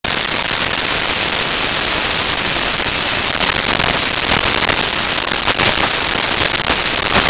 Boat Winch Movement
Boat Winch Movement.wav